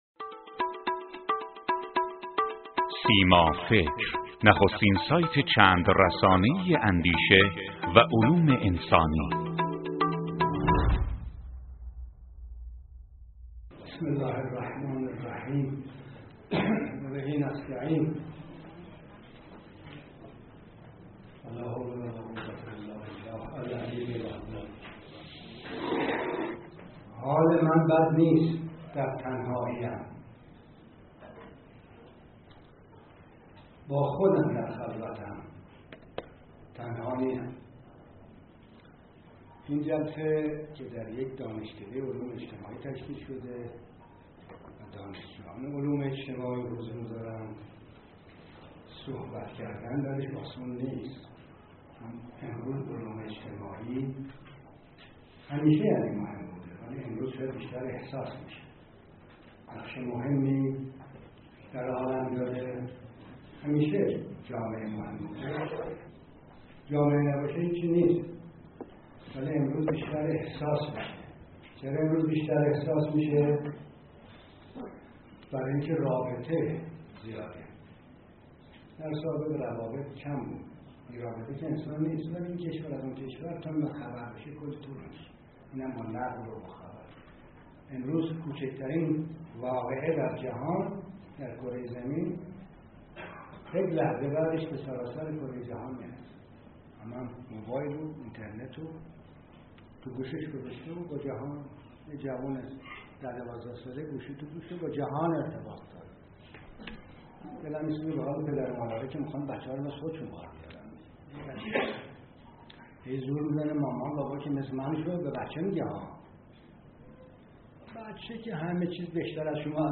این سخنرانی ۱۵ اردیبهشت ۹۴ به همت انجمن علمی دانش اجتماعی مسلمین دانشکده علوم اجتماعی دانشگاه تهران در تالار شریعتی این دانشکده ایراد شده است.